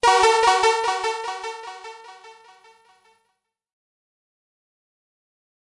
游戏音效 " FX226
描述：爆炸哔哔踢游戏gameound点击levelUp冒险哔哔sfx应用程序启动点击
Tag: 爆炸 单击 冒险 游戏 应用程序 点击的LevelUp 启动 gamesound 哔哔声 SFX